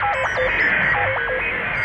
Index of /musicradar/rhythmic-inspiration-samples/130bpm